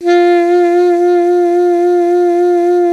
55af-sax06-F3.wav